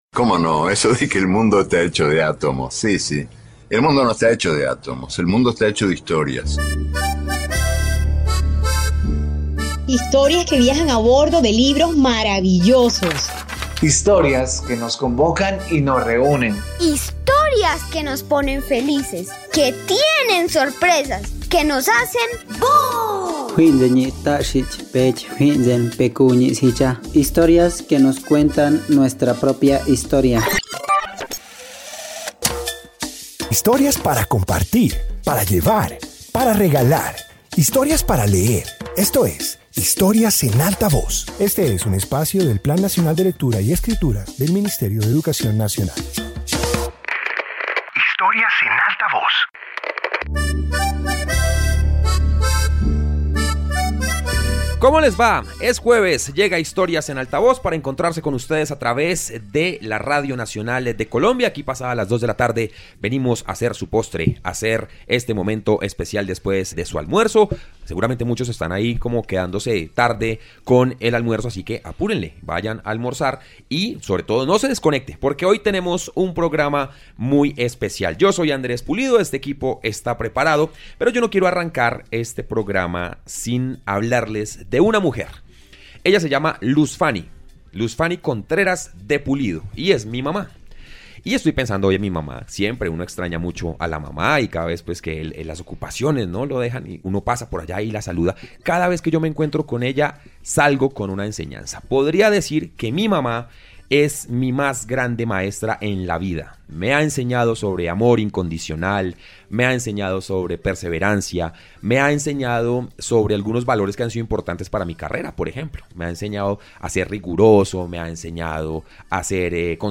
Introducción Este episodio de radio comparte narraciones dedicadas a docentes que han dejado huellas significativas. Presenta recuerdos, anécdotas y momentos que resaltan su papel en la vida de los estudiantes.